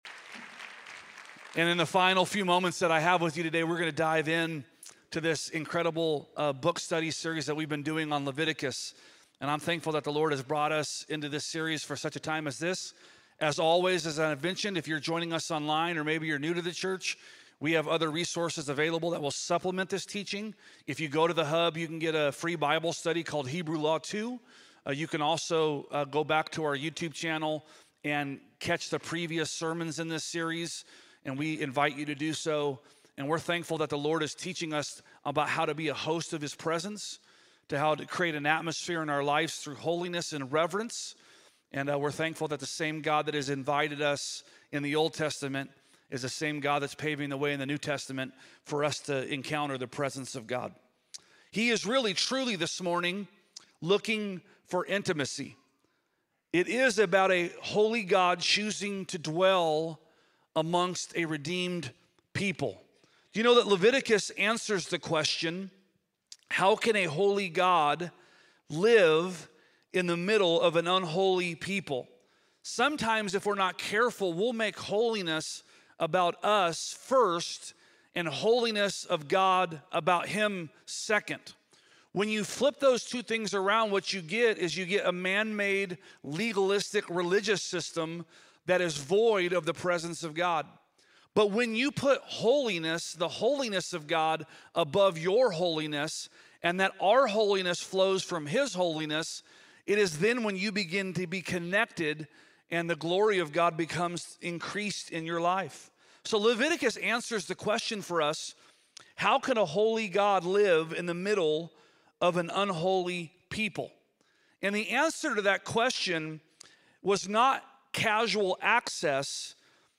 Sermons | Parkway Christian Center